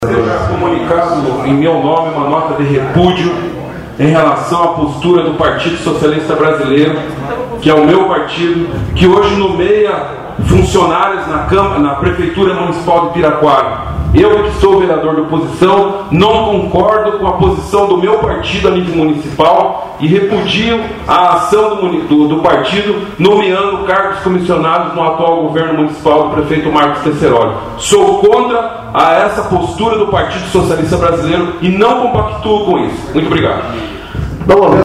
INSATISFEITO VEREADOR FAZ PRONUNCIAMENTO DE REPUDIO
Insatisfeito com acontecimento envolvendo o próprio partido e a prefeitura levou o Vereador Prof. Gilmar a fazer um pronunciamento nas considerações finais na Câmara Municipal de Piraquara nesta terça dia 24/03/2015 veja o audio